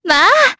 Talisha-Vox_Happy4_Jp.wav